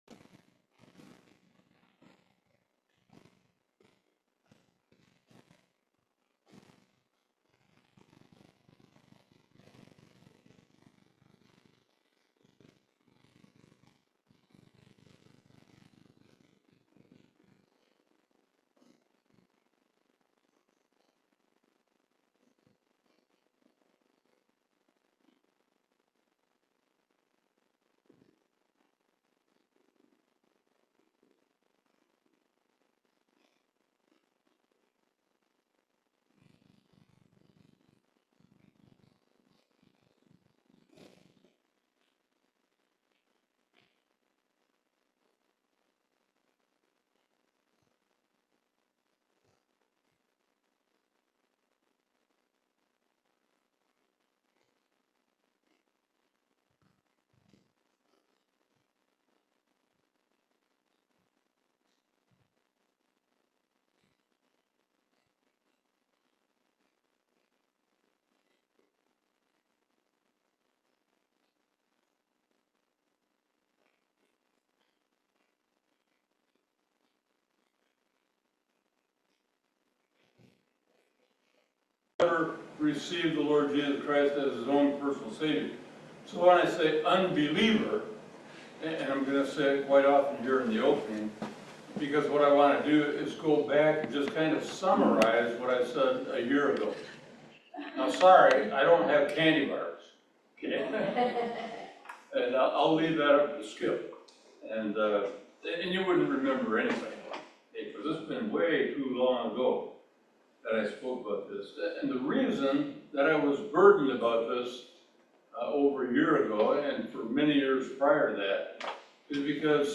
Psalms 51 Service Type: Family Bible Hour For the Christian